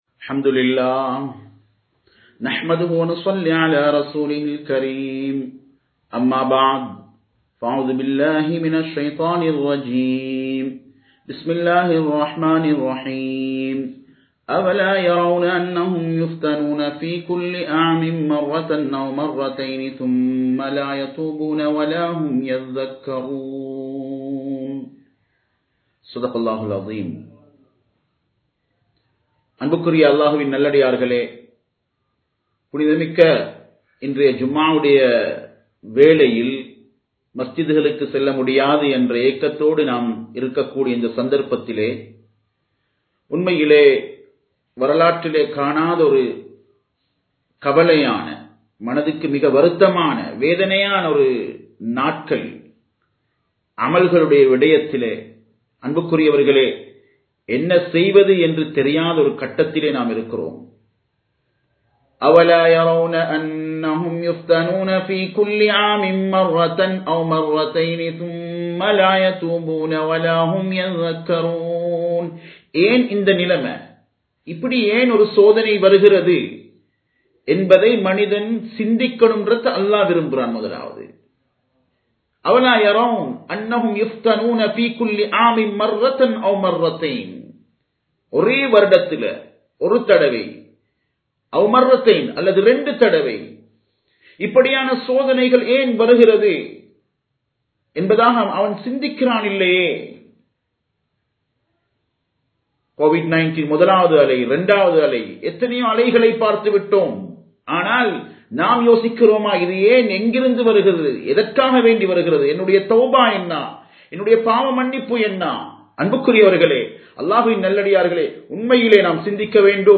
நரகம் தீ மூட்டப்படாத நாள் | Audio Bayans | All Ceylon Muslim Youth Community | Addalaichenai
Live Stream